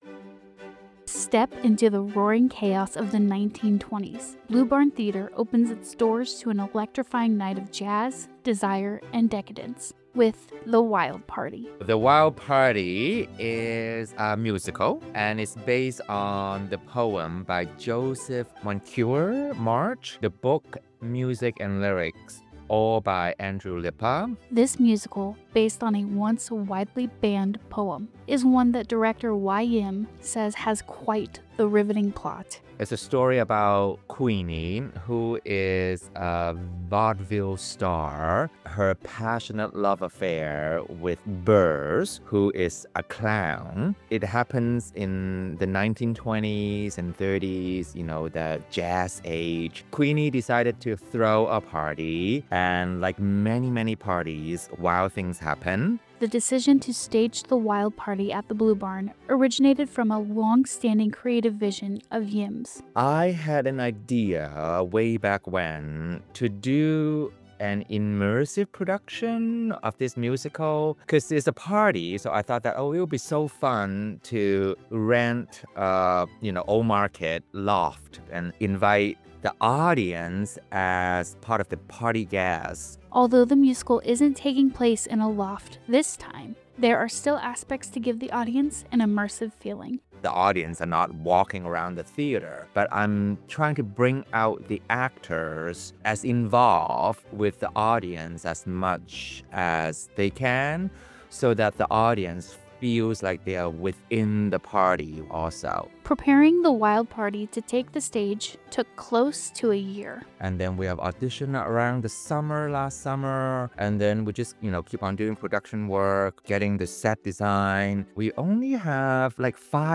Stock Music provided by SonicPulse, from Pond5